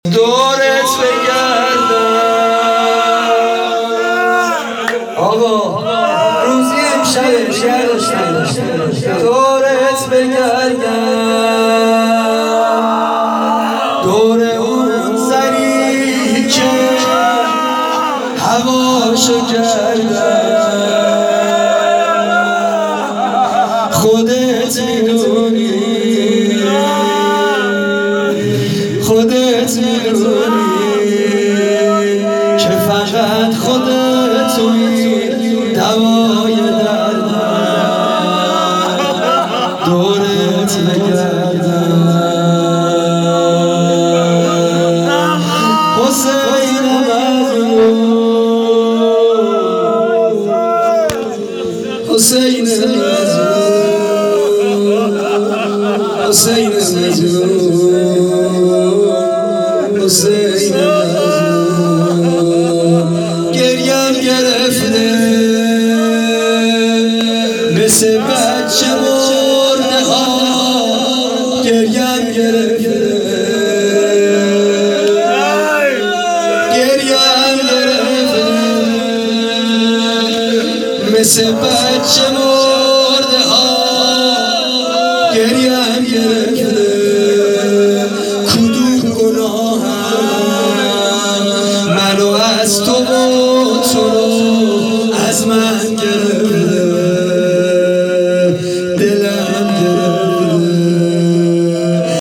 شهادت حضرت جواد الائمه(ع)۹۸